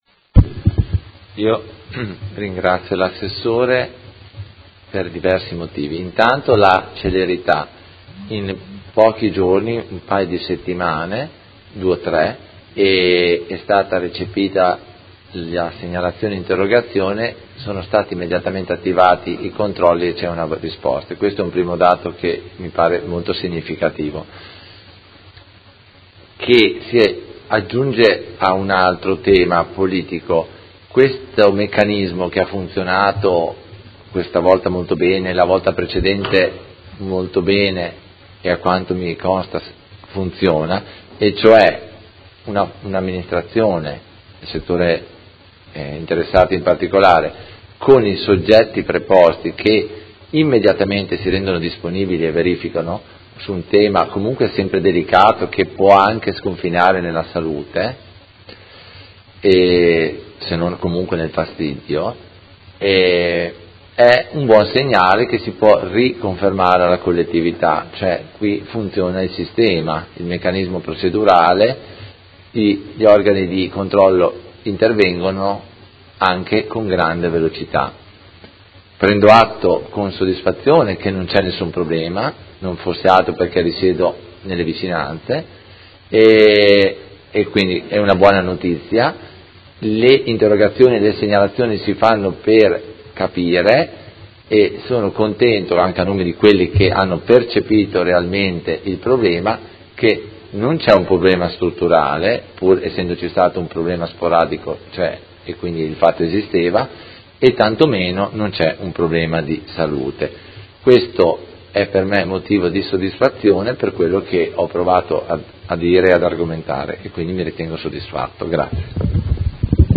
Seduta del 23/11/2017 Replica a risposta Assessore Guerzoni. Interrogazione del Consigliere Carpentieri (PD) avente per oggetto: Miasmi e cattivi odori a Modena est